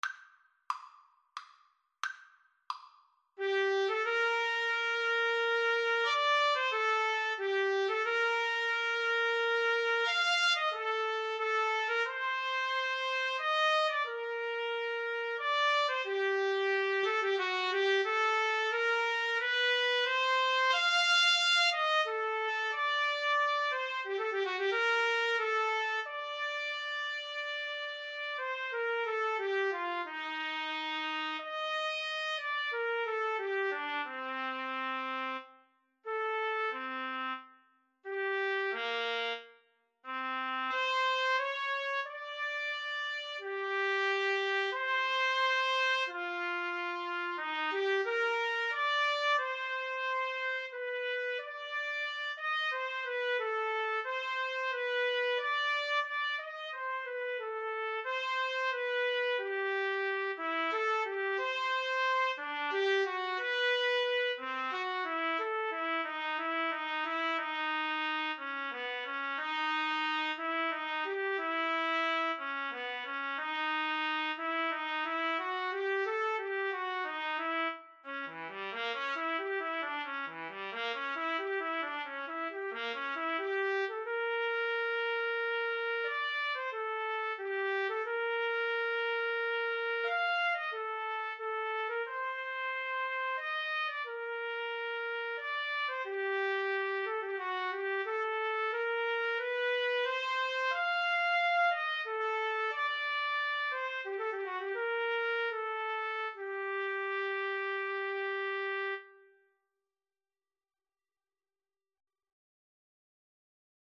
Poco Allegretto = 90
Classical (View more Classical Trumpet-Cello Duet Music)